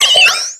infinitefusion-e18/Audio/SE/Cries/PIPLUP.ogg at a50151c4af7b086115dea36392b4bdbb65a07231